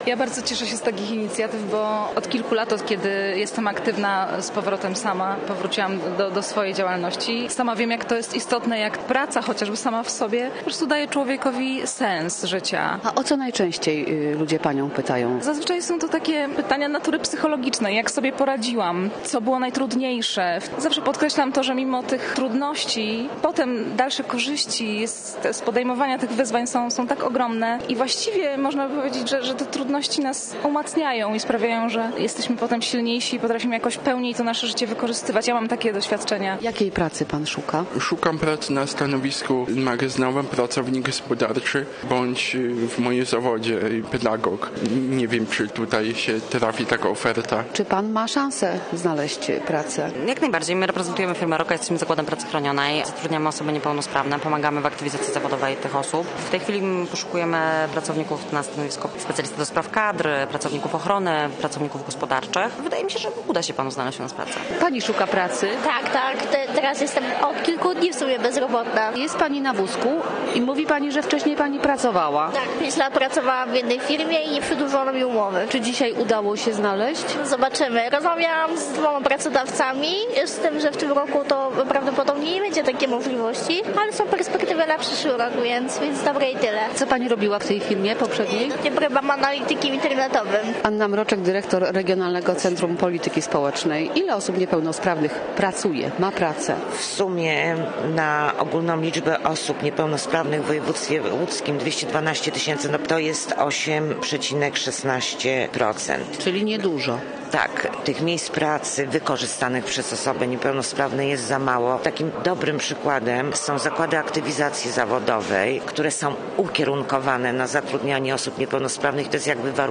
Posłuchaj relacji: Nazwa Plik Autor Aktywizacja niepelnosprawnych audio (m4a) audio (oga) Warto przeczytać Fly Fest 2025.